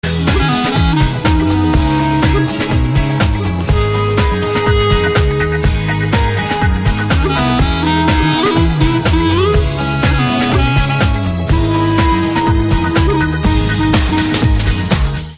Новая,pre-processed библиотека ударных